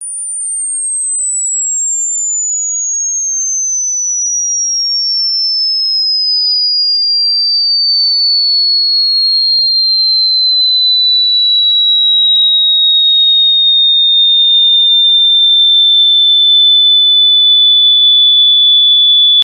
Dog Whistle
Dog whistle sound mixed with a slightly lower frequency sound so that humans can hear it as well.